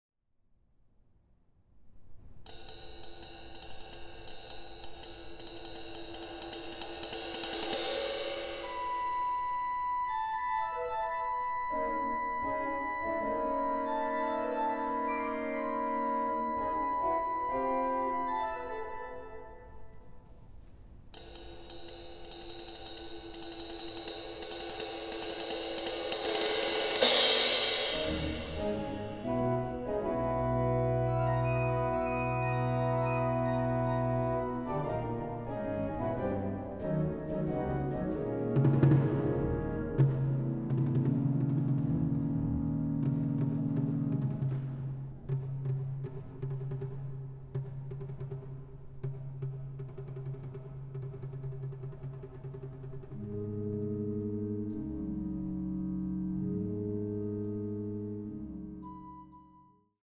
Orgel
Perkussion
Gong
Aufnahme: Het Orgelpark, Amsterdam, 2023